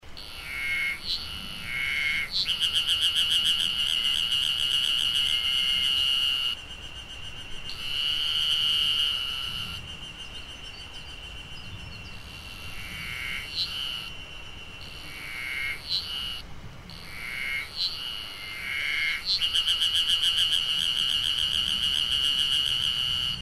エゾハルゼミ
エゾハルゼミの鳴き声はこちら
剣山　６月２３日